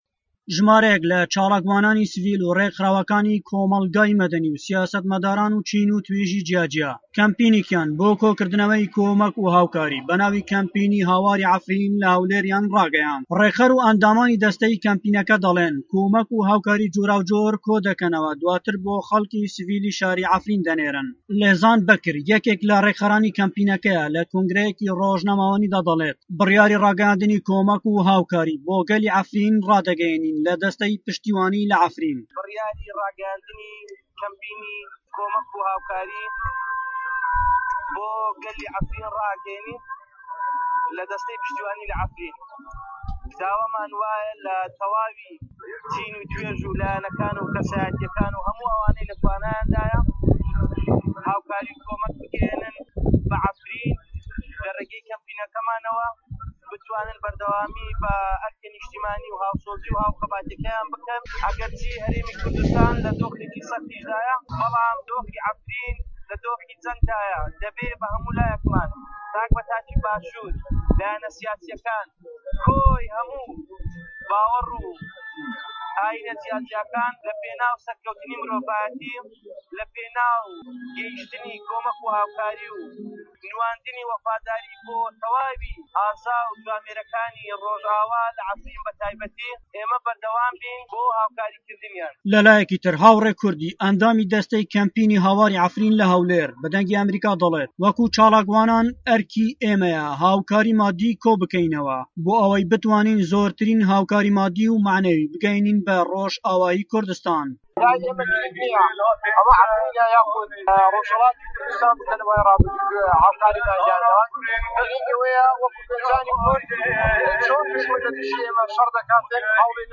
لە کۆنگرەیەکی ڕۆژنامەوانیدا